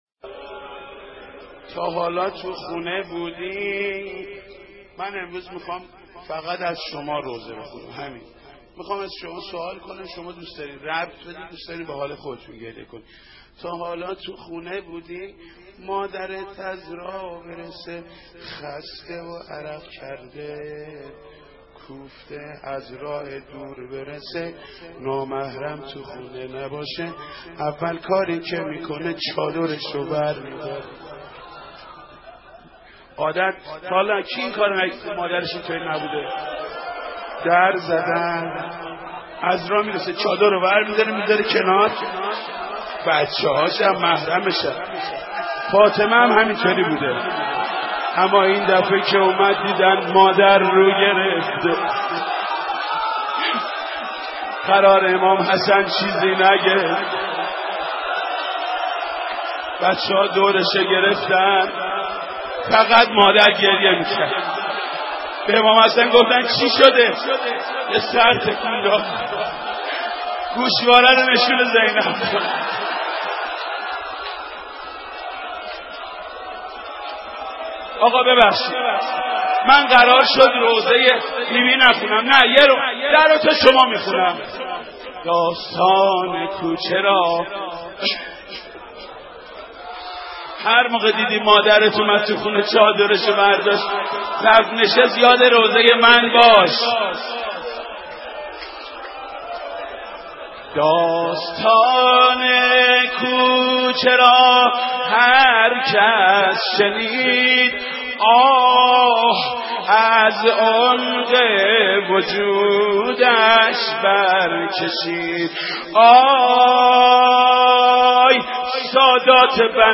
دانلود مداحی داستان کوچه هر کس شنید…
روضه خوانی محمود کریمی در سوگواری حضرت زهرا (س) (6:38)